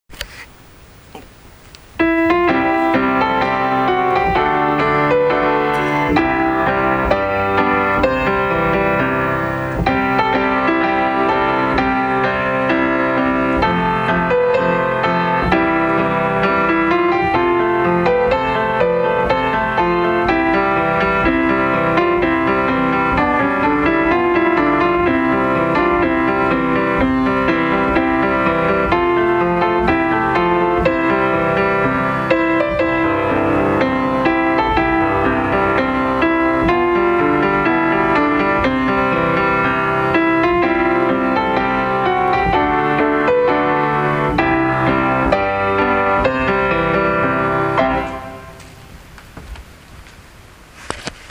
＜校歌について＞